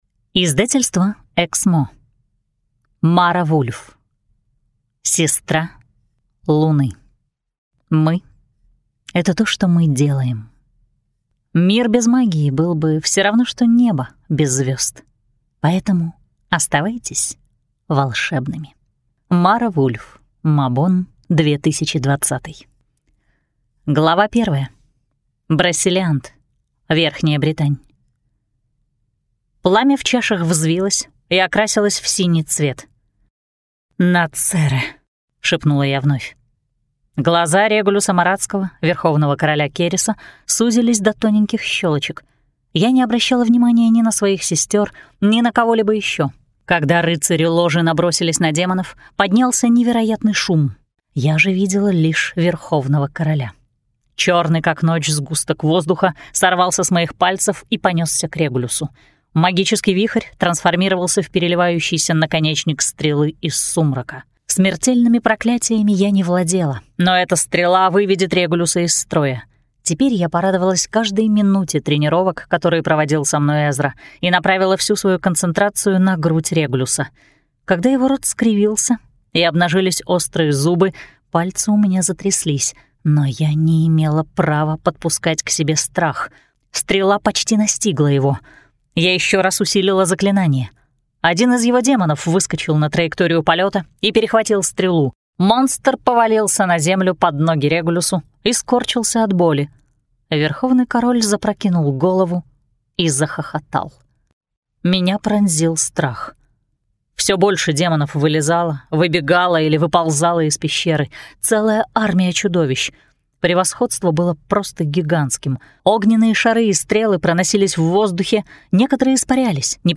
Аудиокнига Сестра луны | Библиотека аудиокниг
Прослушать и бесплатно скачать фрагмент аудиокниги